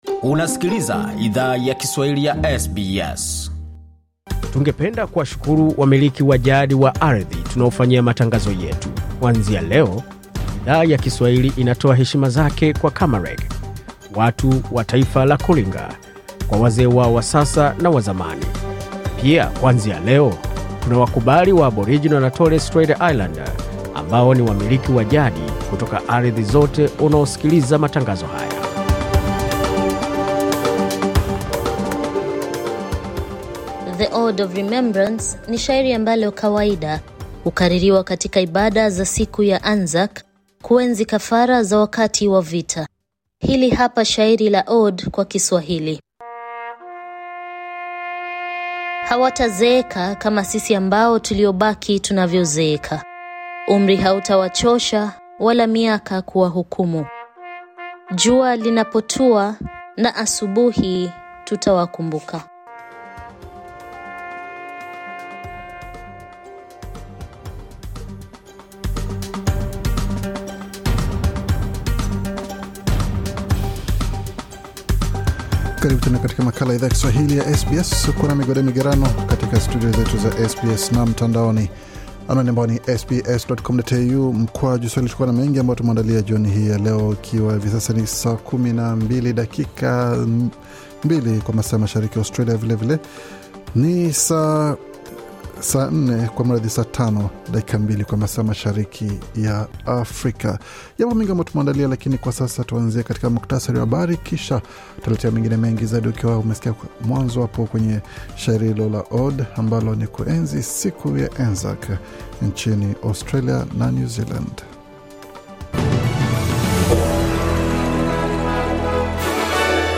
Taarifa ya Habari 25 Aprili 2023